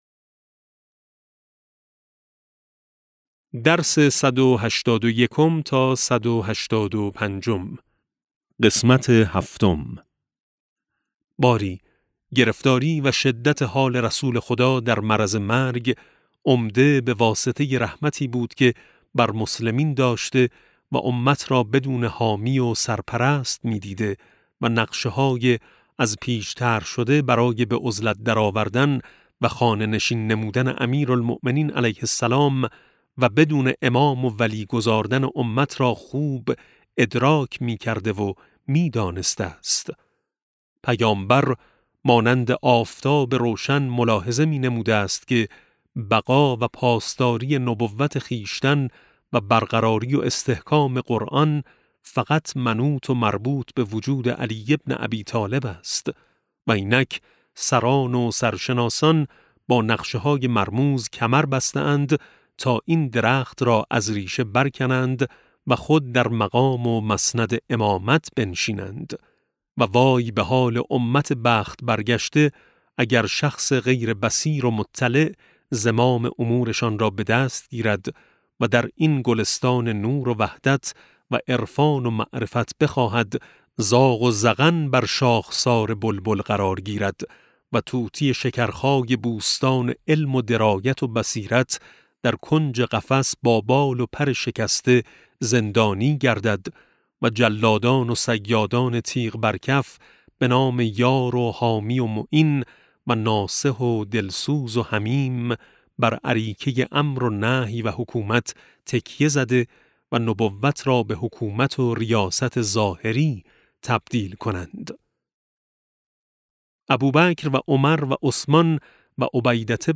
کتاب صوتی امام شناسی ج 13 - جلسه7